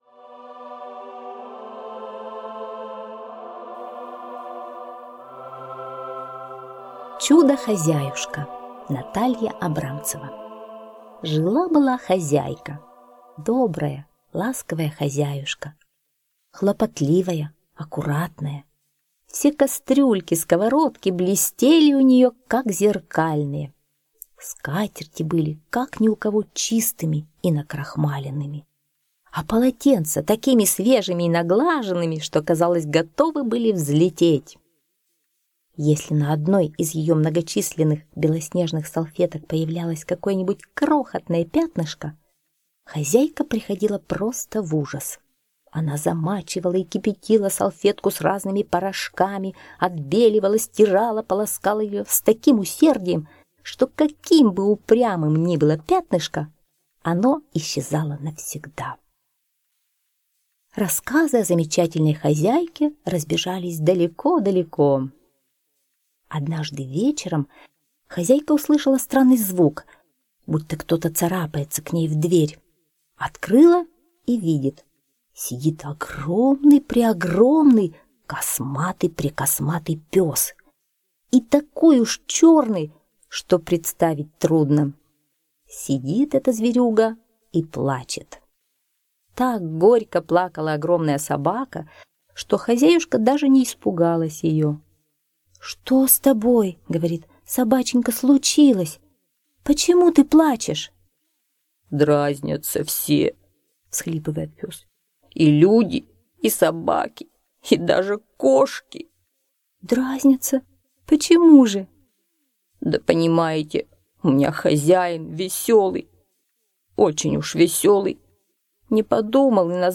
Чудо хозяюшка - аудиосказка Абрамцевой Н. История про очень аккуратную хозяйку, у которой дома все блестело от чистоты, и черного лохматого пса.